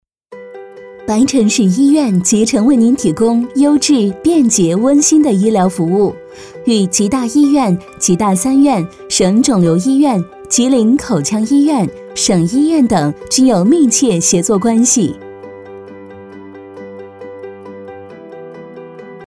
女10号配音师
声音风格多变，可配几岁小朋友的声音，也可演绎七八十岁老年人的声音。